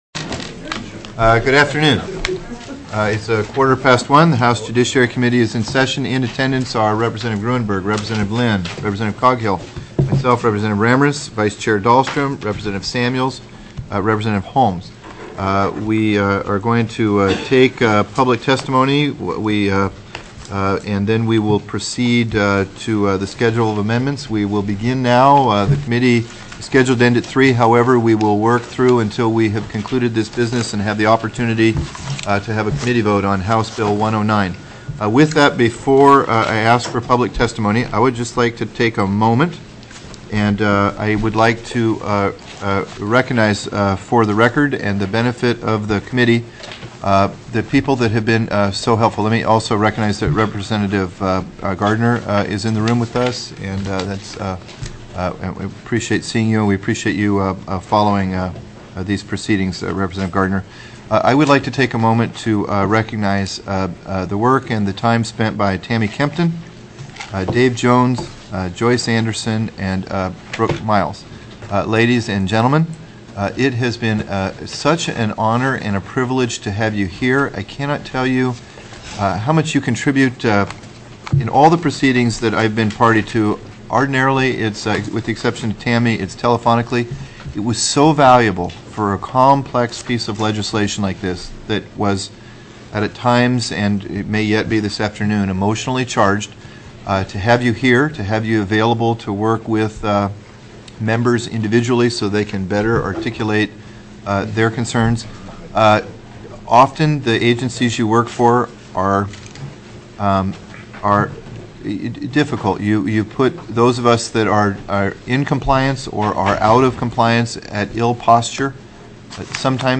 03/23/2007 01:00 PM House JUDICIARY